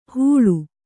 ♪ hūḷu